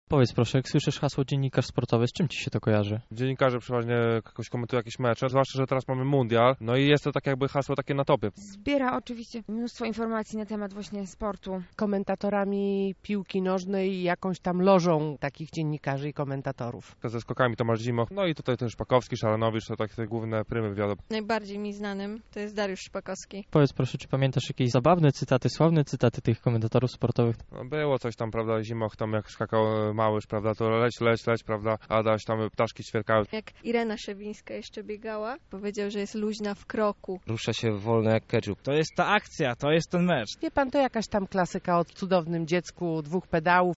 A co o pracy dziennikarza sportowego wiedzą słuchacze:
sonda dzień dziennikarza s.
sonda-dzień-dziennikarza-s..mp3